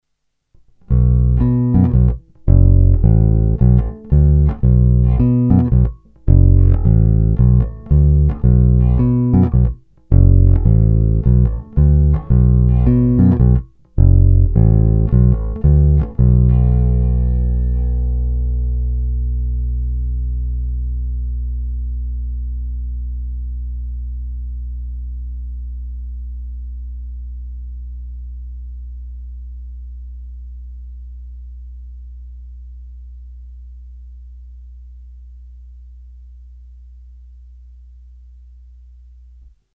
Bručivá, agresívnější, skvěle použitelná i na slapovou techniku.
Není-li uvedeno jinak, všechny nahrávky byly provedeny rovnou do zvukové karty a dále kromě normalizace ponechány bez úprav. Tónová clona byla vždy plně otevřená.
Hra mezi krkem a snímačem